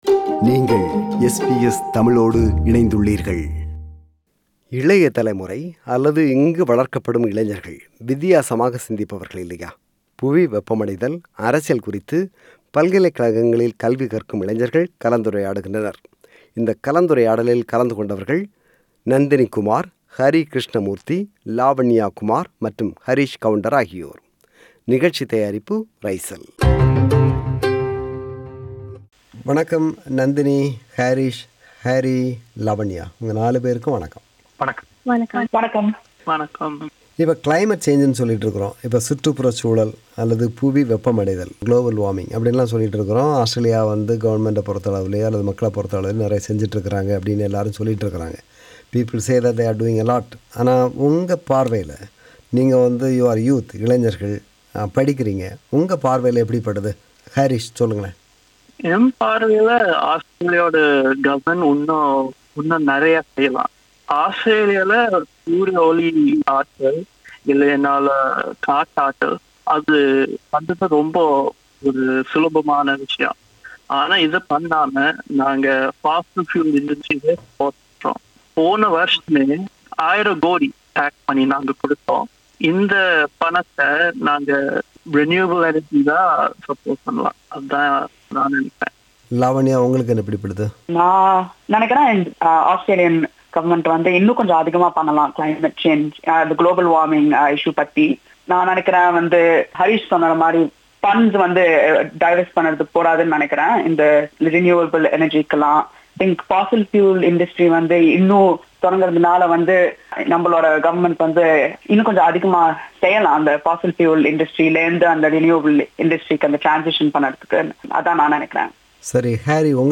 A panel discussion with Tamil youth